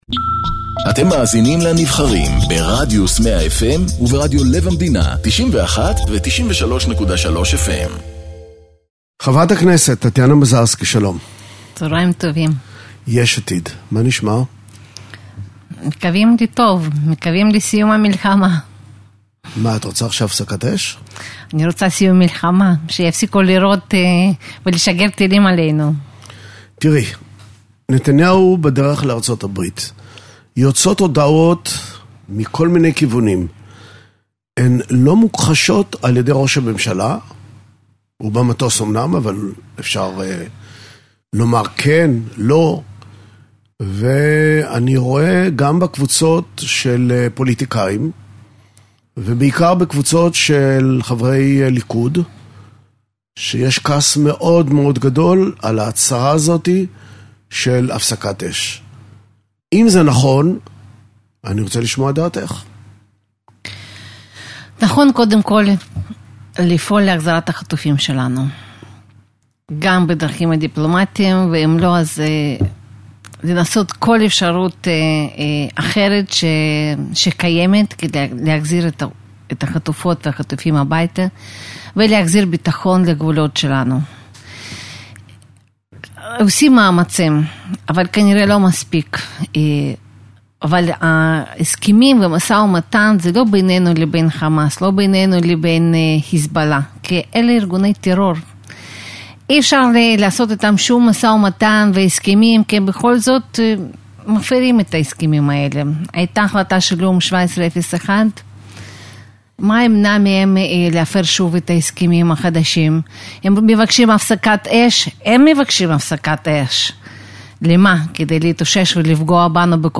מראיין את חברת הכנסת טטיאנה מזרסקי